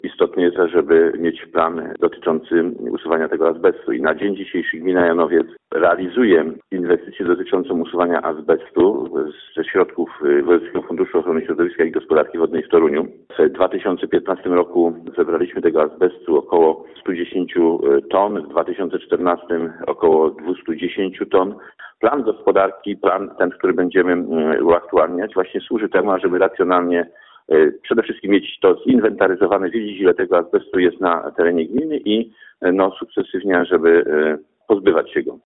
Dokumentem wspomagającym, który określa działania w zakresie usuwania azbestu w skali gminy jest Program usuwania azbestu i wyrobów zawierających azbest, do opracowania którego przystąpiła właśnie Gmina Janowiec Wielkopolski - mówi Burmistrz Maciej Sobczak.